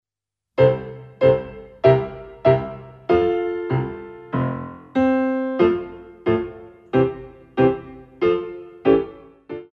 34. March